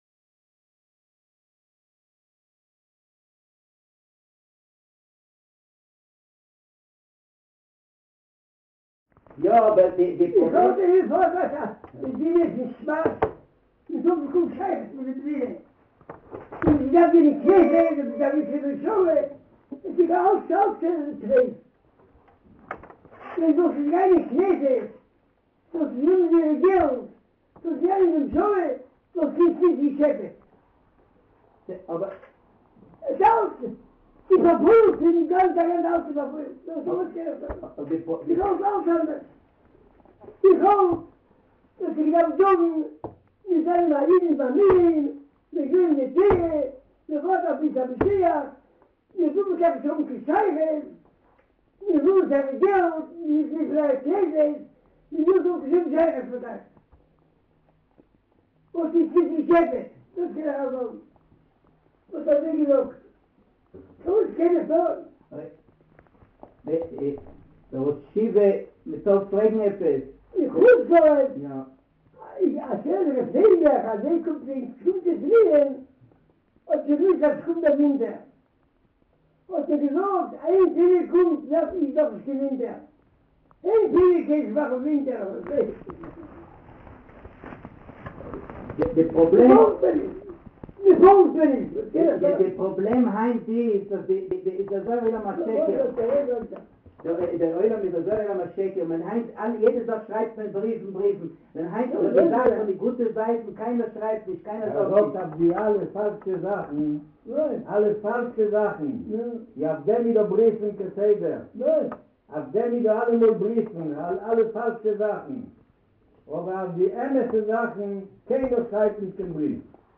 הקלטה נדירה של דעת תורה הצרופה מפי הגאון מבריסק על עוון השתתפות בבחירות